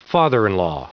Prononciation du mot father-in-law en anglais (fichier audio)
Prononciation du mot : father-in-law
father-in-law.wav